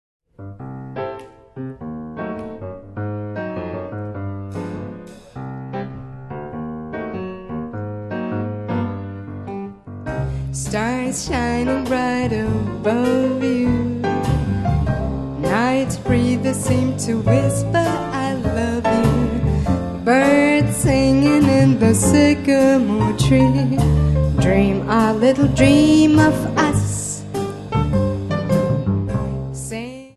Gesang
Piano
Kontrabass
Schlagzeug